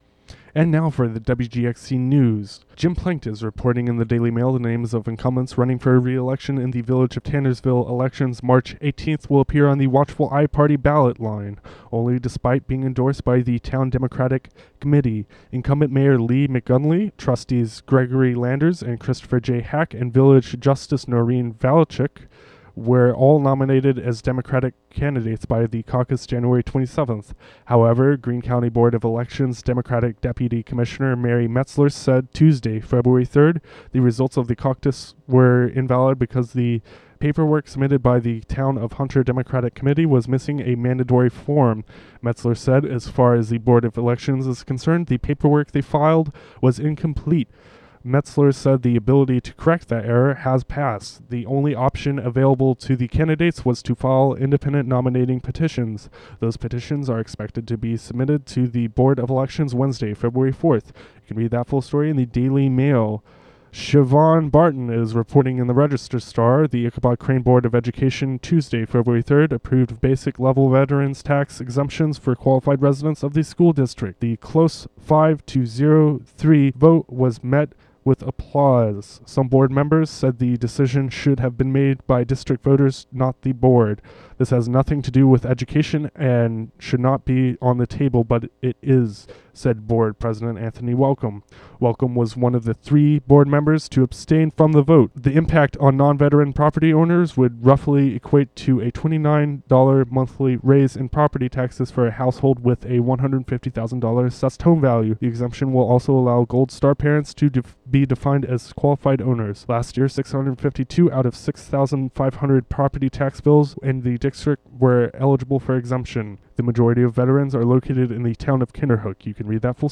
Local news and weather for Wednesday, February 4, 2015.